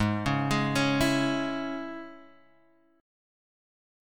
Absus4#5 chord